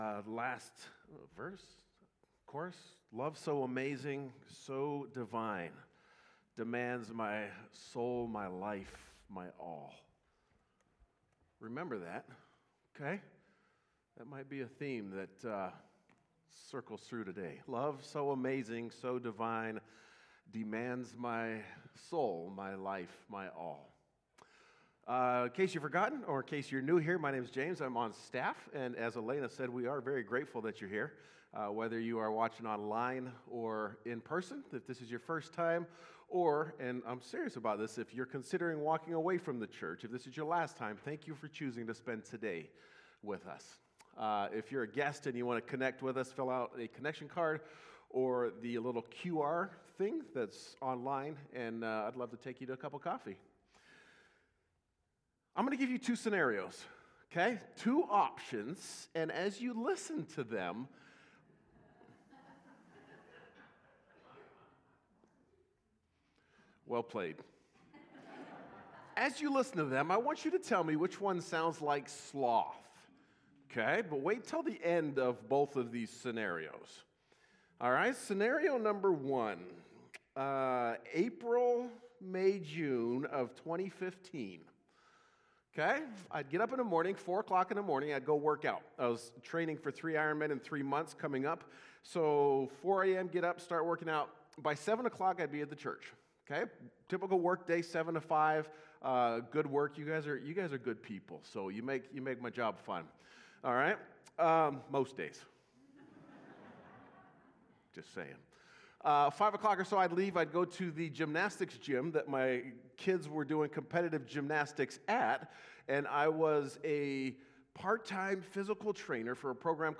Sermons by First Free Methodist Spokane